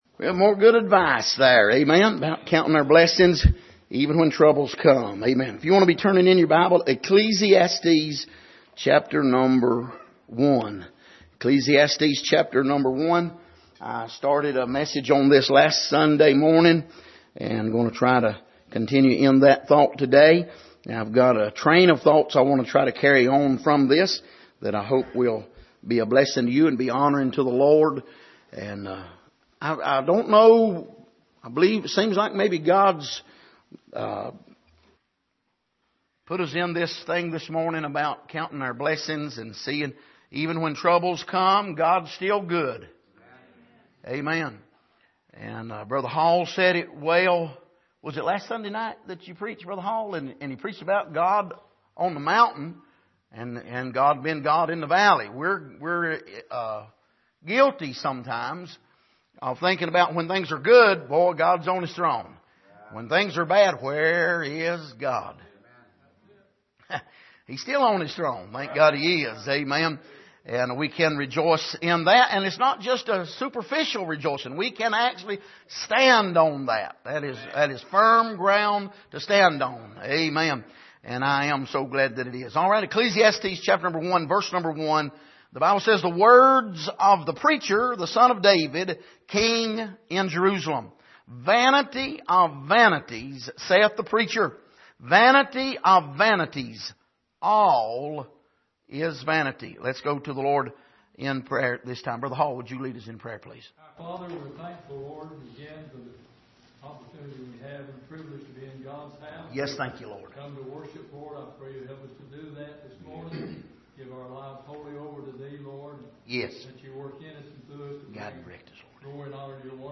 Passage: Ecclesiastes 1:1-2 Service: Sunday Morning